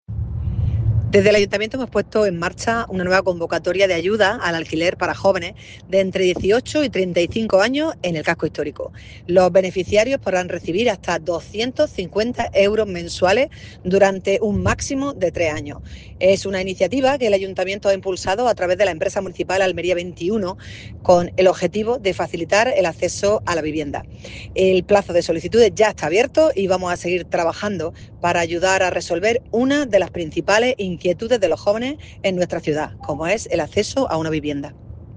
ALCALDESA-AYUDAS-ALQUILER-CASCO-HISTORICO.mp3